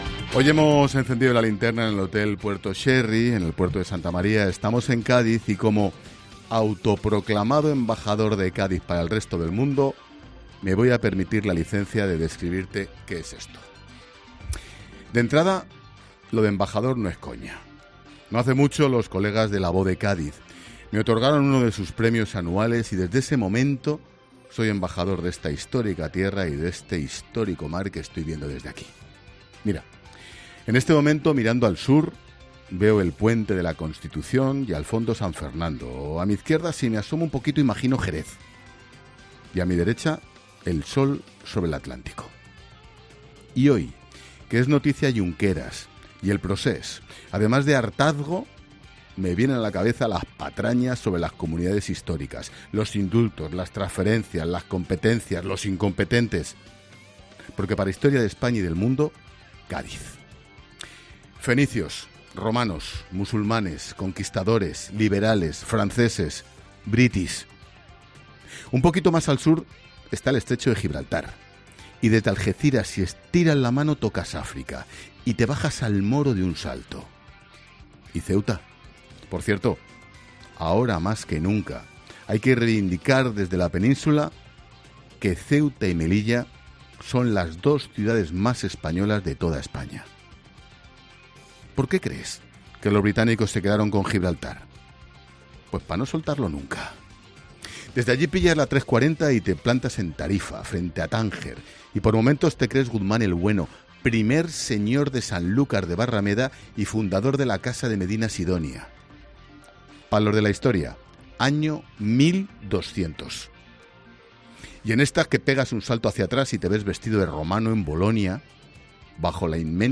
AUDIO: El director de 'La Linterna' ha viajado este lunes hasta Cádiz, desde donde habla de la historia de nuestro país
Monólogo de Expósito